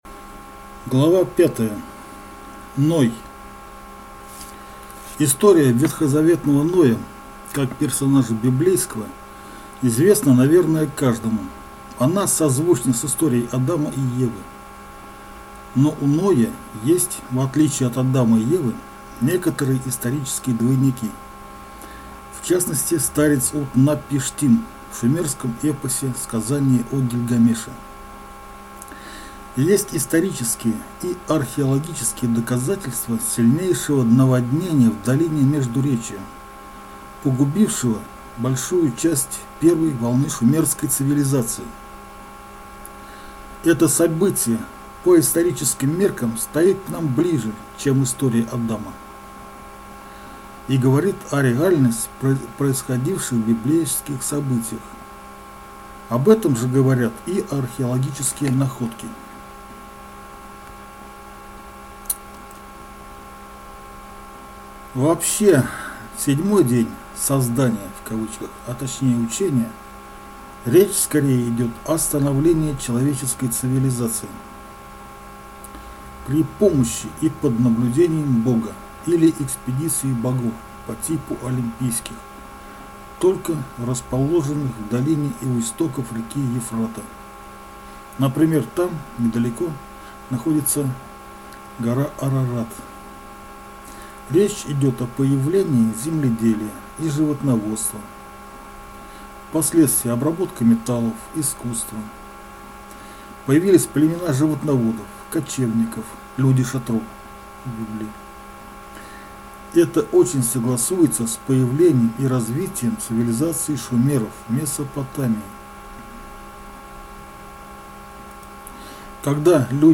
Аудиокнига Историческая ошибка Библии.